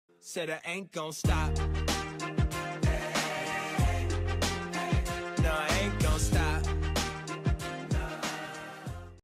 sol-aint-gon-stop-notification-alert-sound_MbgaEBF.mp3